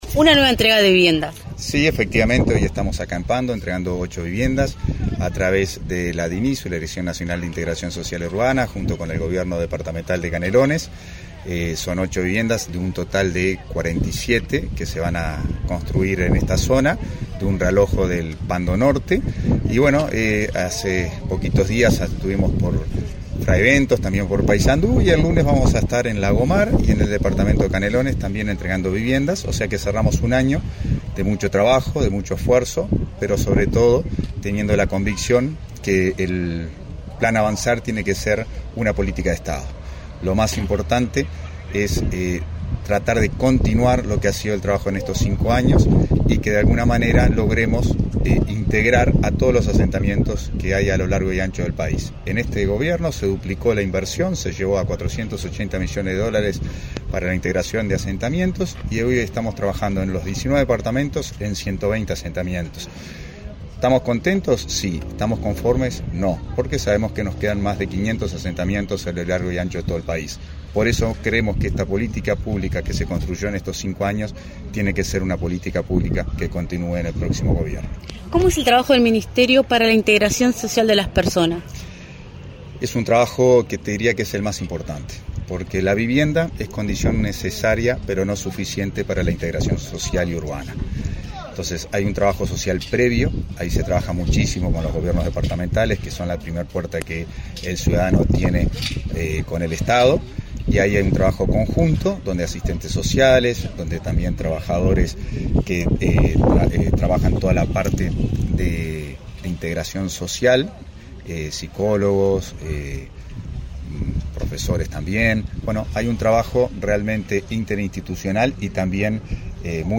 Entrevista al subsecretario del MVOT, Tabaré Hackenbruch
Entrevista al subsecretario del MVOT, Tabaré Hackenbruch 20/12/2024 Compartir Facebook X Copiar enlace WhatsApp LinkedIn Tras participar en la entrega de viviendas en Pando Norte, en el marco del Plan Avanzar, este 20 de diciembre, el subsecretario del Ministerio de Vivienda y Ordenamiento Territorial (MTOV), Tabaré Hackenbruch, realizó declaraciones a Comunicación Presidencial.